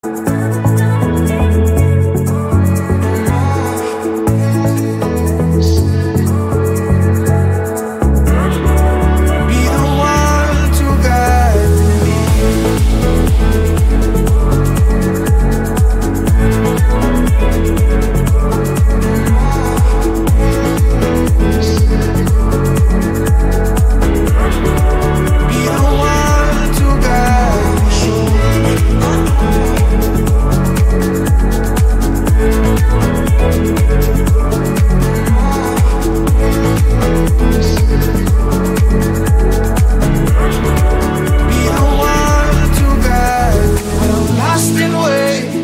Catégorie Électronique